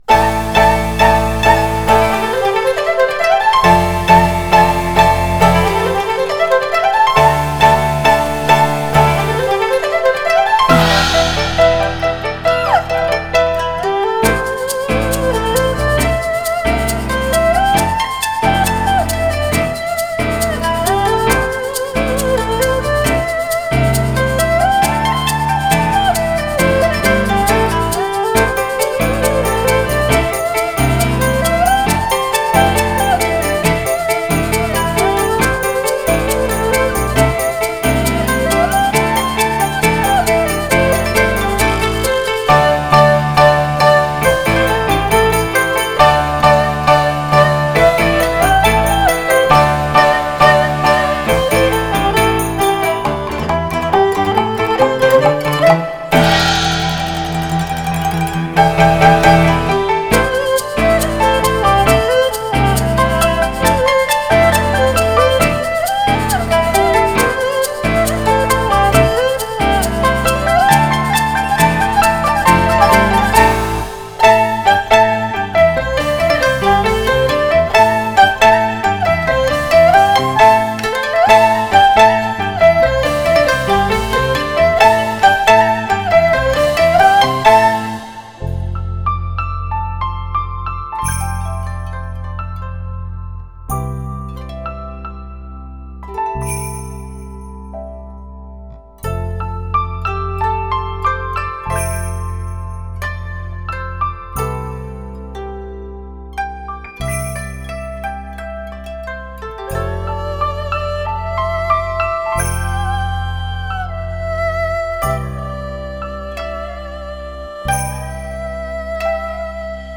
古筝
音乐风格：轻音乐 (Easy Listening) / 纯音乐 (Pure Music) / 民谣 (Folk)